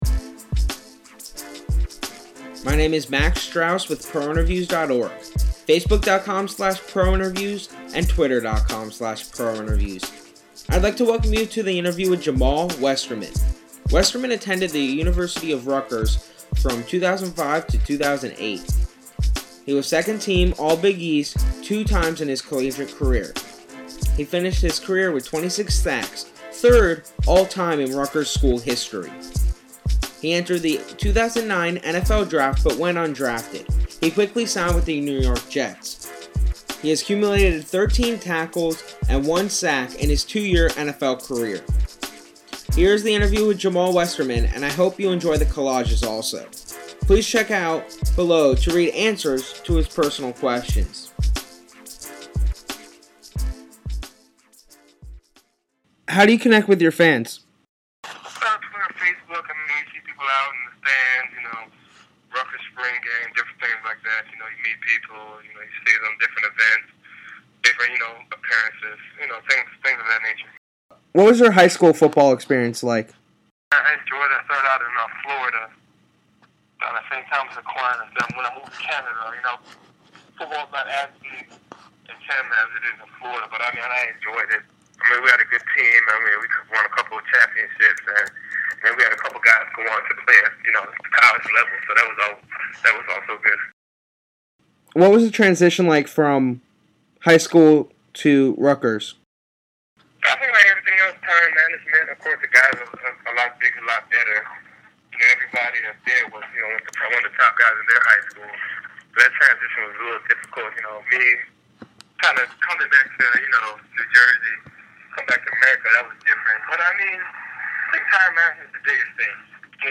This interview was conducted before the 2012 NFL season.